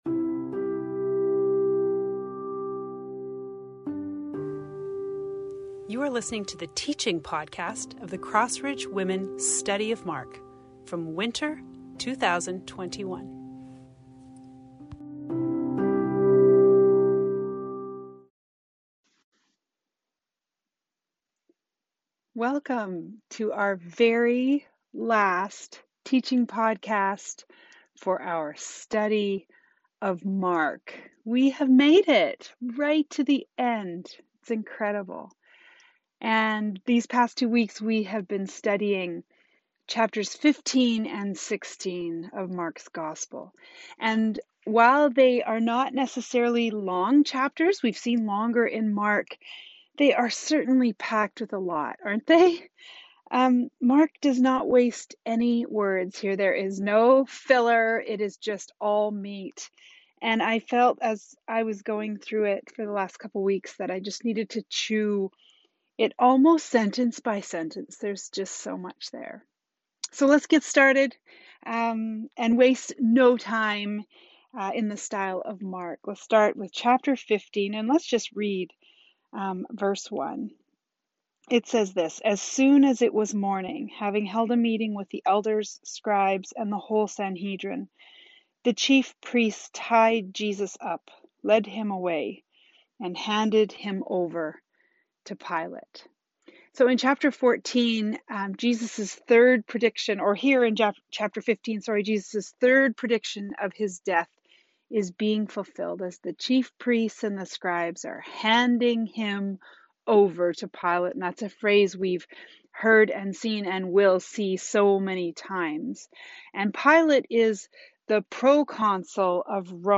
Teaching Podcast from Mark 15-16:8.